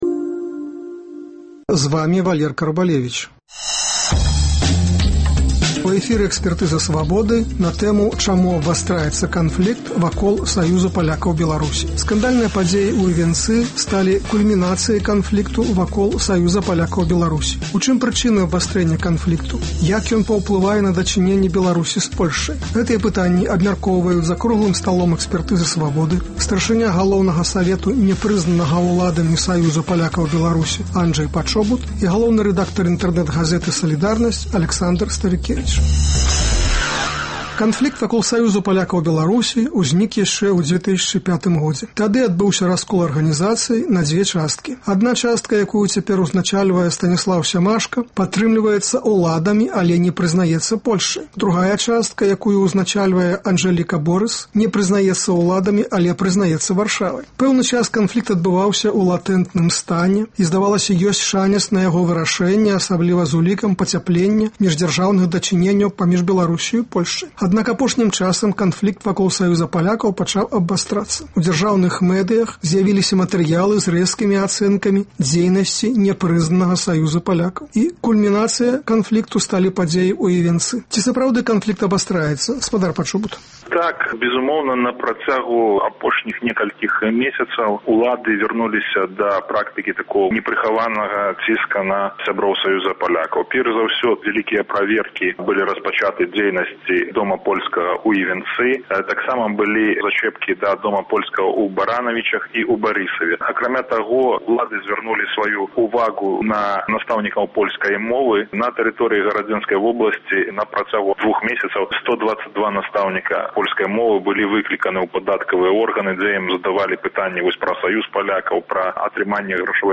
Як ён паўплывае на дачыненьні Беларусі з Польшчай? Гэтыя пытаньні абмяркоўваюць за круглым сталом